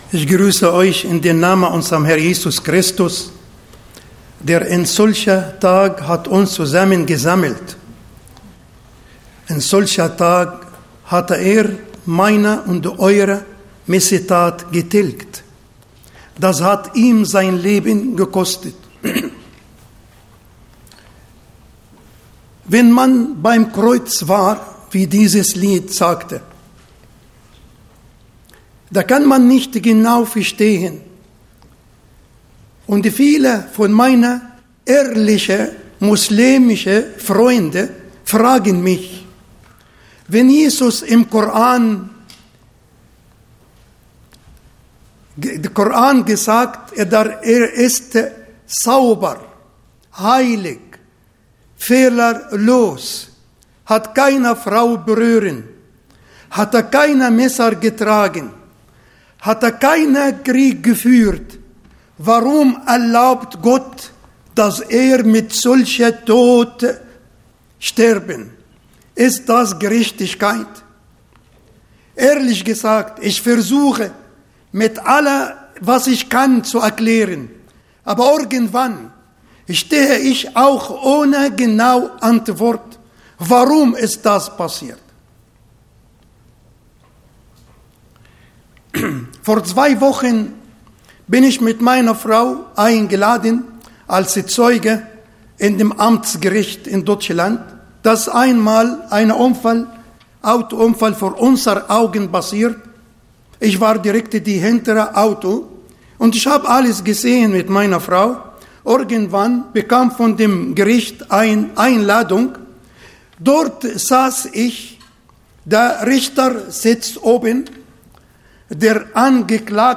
Botschaft